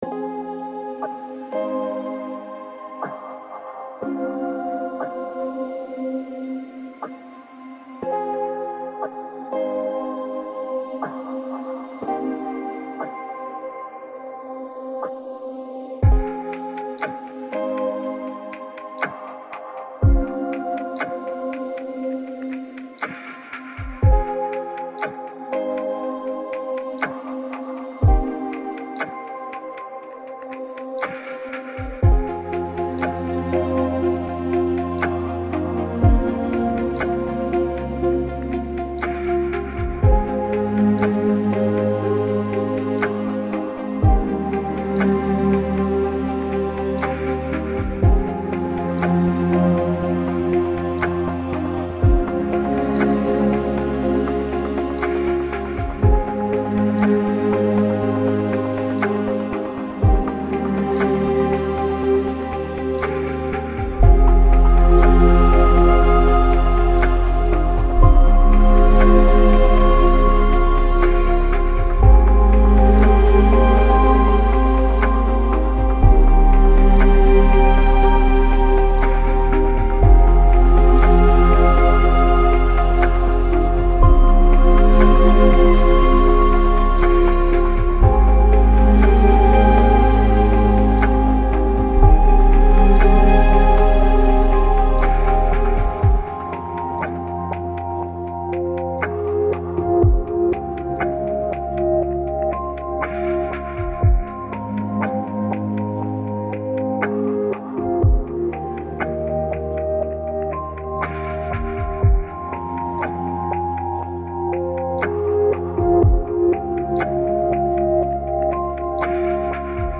Жанр: Lounge, Chill Out, Downtempo